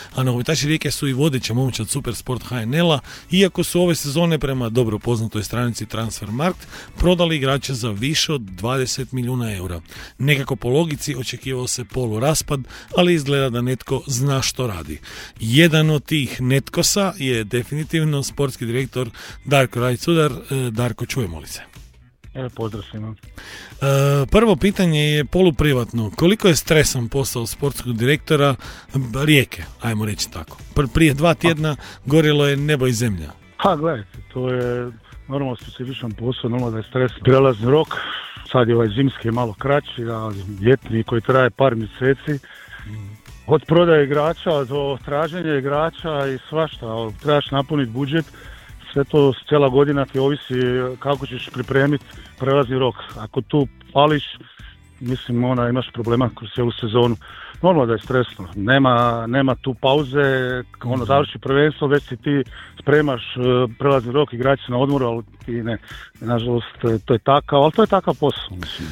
Ali, gostovanje na radiju bilo je usmjereno prema poslu sportskog direktora prvoligaša. Upitali smo ga – koliko je stresan taj posao?